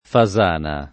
Fasana [ fa @# na ]